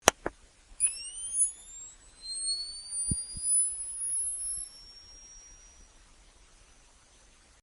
SFX
yt_gkLhO9ZO3j0_night_vision_on.mp3